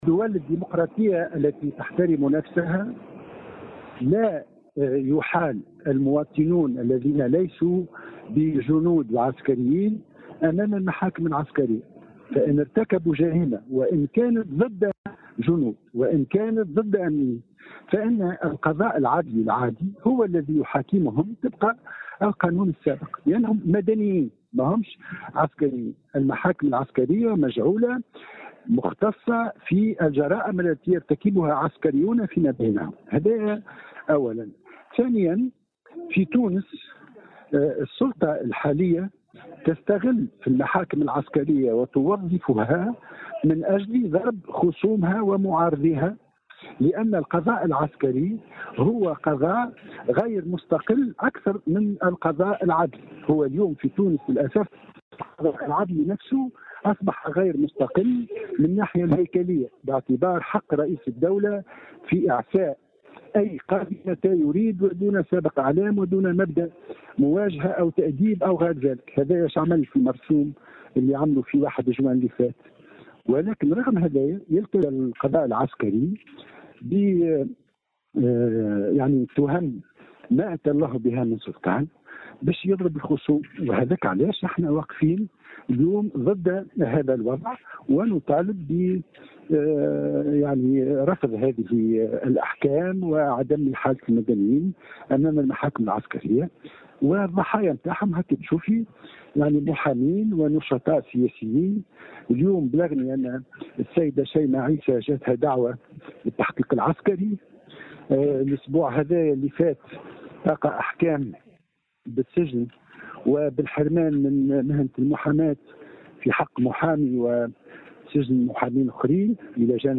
Ayachi Hammami dénonce le recours à la justice militaire pour juger les civils (Déclaration)
Dans une déclaration faite, mardi 24 janvier 2023 à Tunisie Numérique, Hamami a fait savoir que le problème aujourd’hui en Tunisie est que le pouvoir utilise ces tribunaux contre ses opposants, considérant que la justice militaire n’est pas plus indépendante .